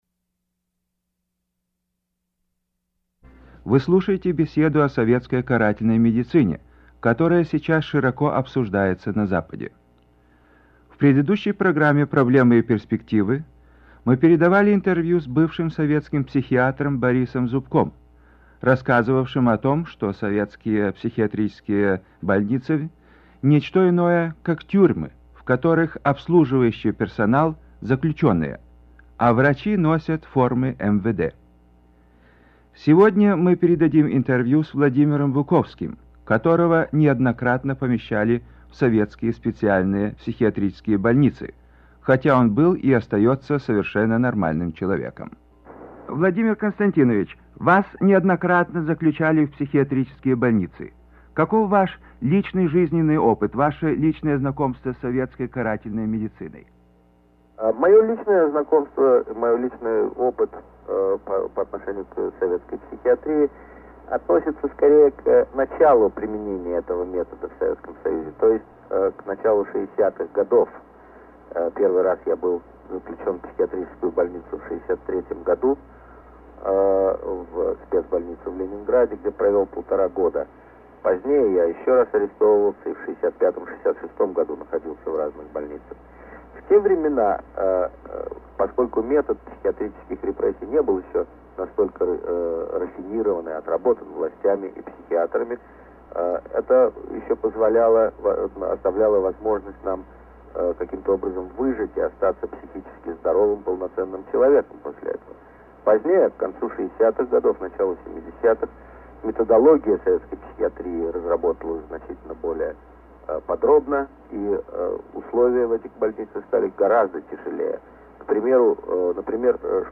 Радиоинтервью с Владимиром Буковским